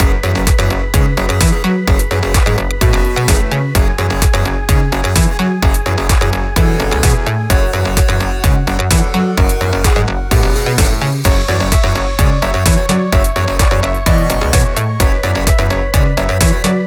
Addictional-Mastering_Wine.mp3